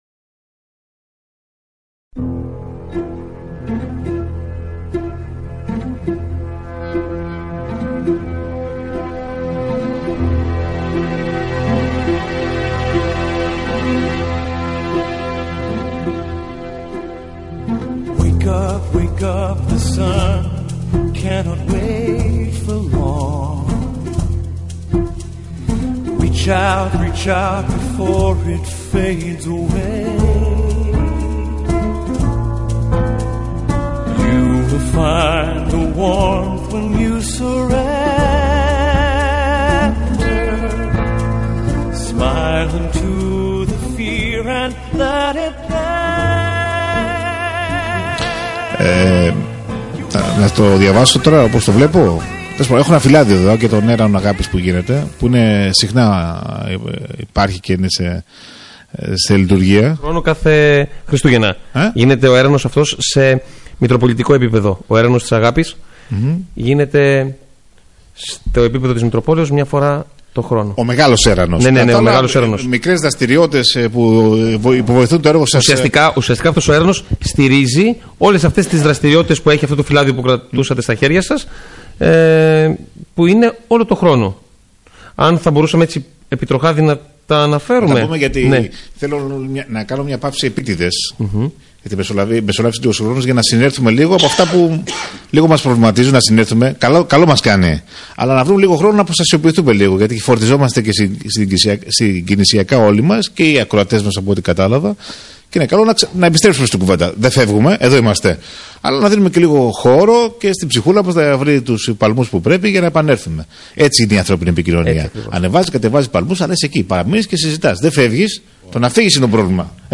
Η “ΓΙΑΦΚΑ” του Alpha στον Άγιο Αθανάσιο